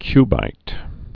(kybīt)